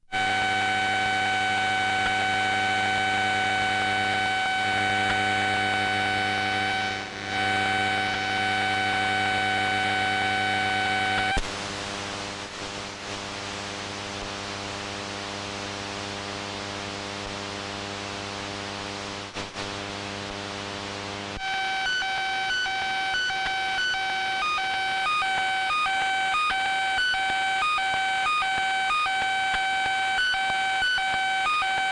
描述：来自AT＆amp; T的电磁干扰;无绳电话听筒CL82301靠近内部铁氧体天线，靠近AM广播频段底部附近一个有13年历史的扬声器箱的右后方。从线路输入Goldwave记录。
你听到单声道的待机充电音，手机发出嗡嗡声，然后一声咔哒一声，当我把它从充电器上取下来时，手机上发出一连串低音低音待机音。
Tag: 点击 无线电干扰 充电器 是无线电 嗡嗡声 EMF EMI 毛刺 蜂鸣 电磁 脉冲声 嘶嘶声 干扰 数字 低保 哼哼 电子 噪声 无绳电话